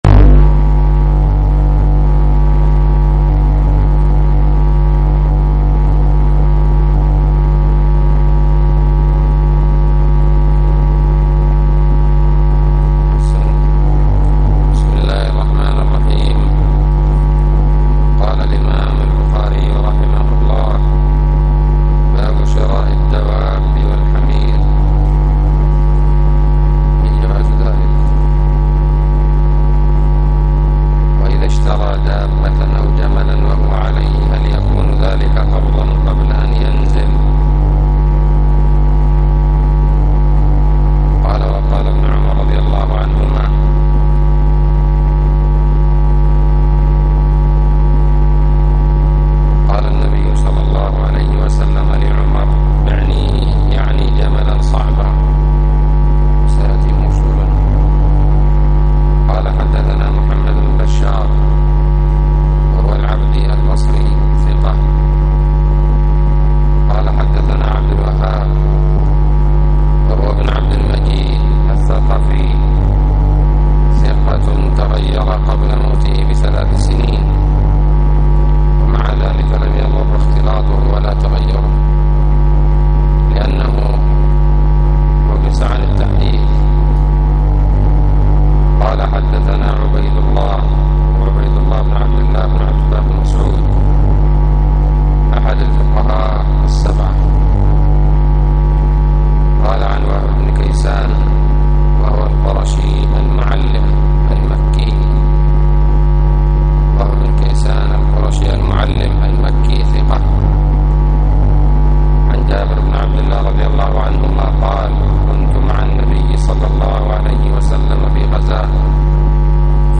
الدرس الثلاثون من كتاب البيوع من صحيح الإمام البخاري
بسم الله الرحمن الرحيم الدرس الثلاثون : بَاب: شِرَاءِ الدَّوَابِّ وَالْحُمُرِ، وَإِذَا اشْتَرَى دَابَّةً أَوْ جَمَلًا وَهُوَ عَلَيْهِ، هَلْ يَكُونُ ذَلِكَ قَبْضًا قَبْلَ أَنْ يَنْزِلَ؟(ملاحظة:هذا الدرس ليس واضحا لخلل في جهاز التسجيل)